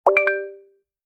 abstract-sound4.wav